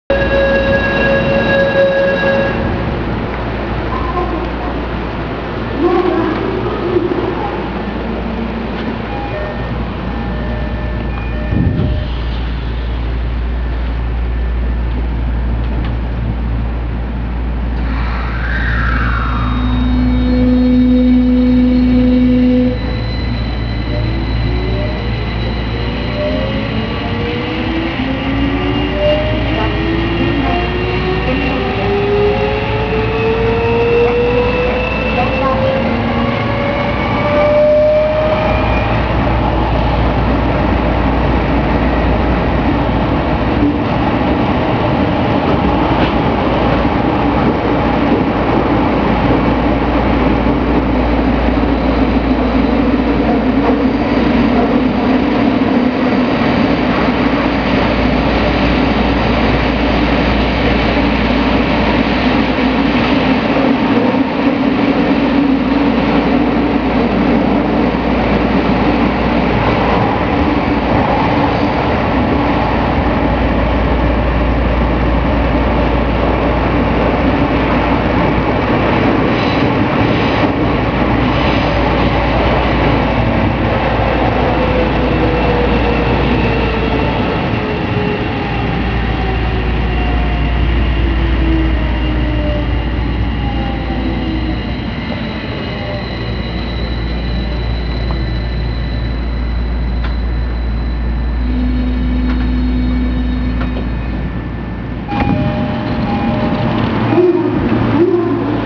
・16000系2次車まで走行音
【千代田線】西日暮里〜千駄木（1分49秒：591KB）
今までの車両に無かった走行音が目立っています。